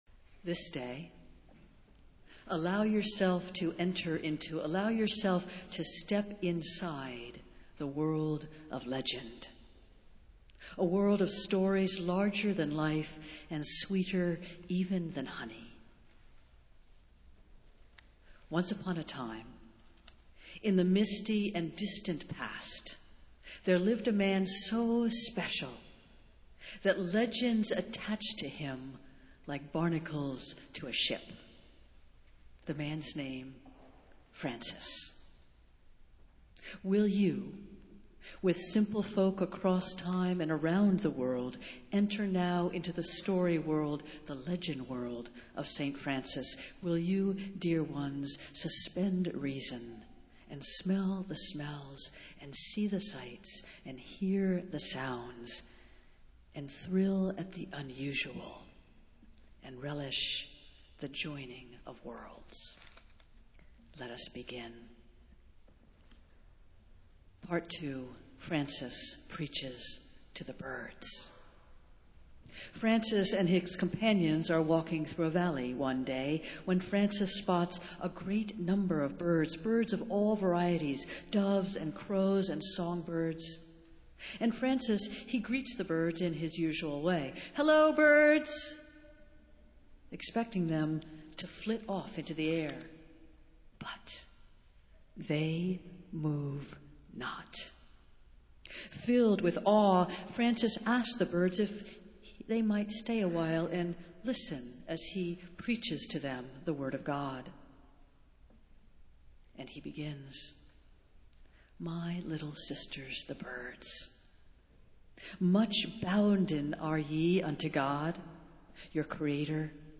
Festival Worship - Saint Francis Sunday